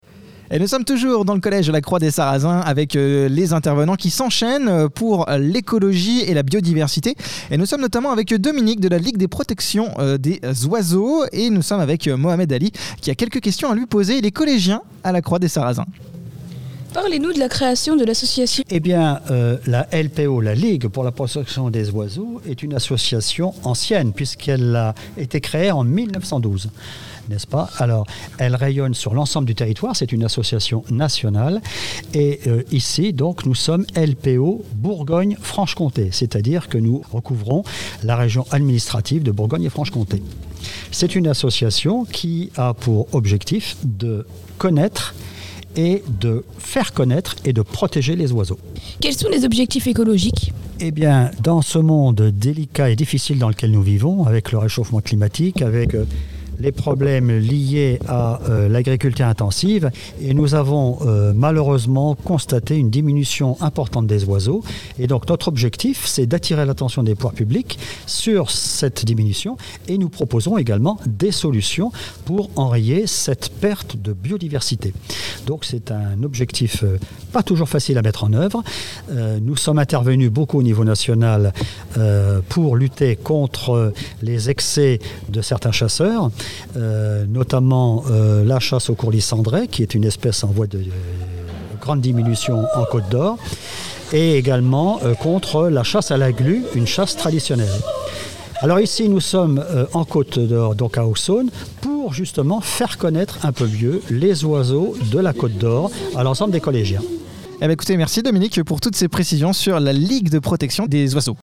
Interview de la Ligue de Protections des Oiseaux